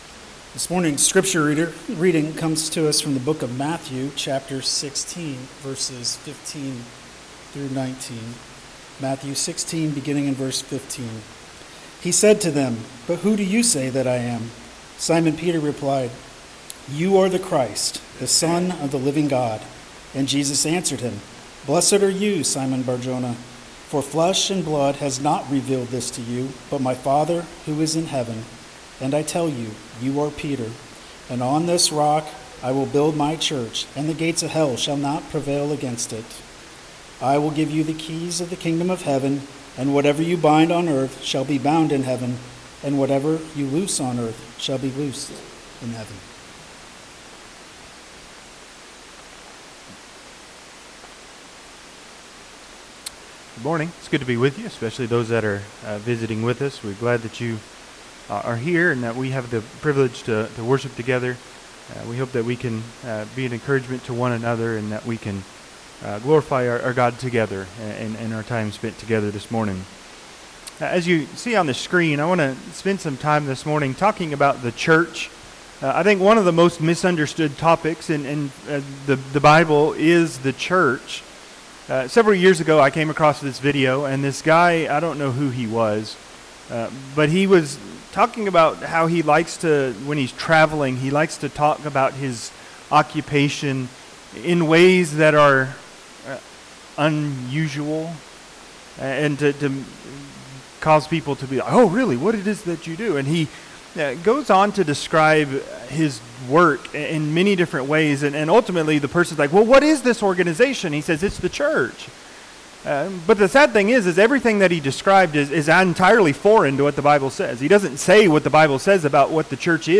Matthew 16:15-19 Service Type: Sunday AM What is the Church as God defines it?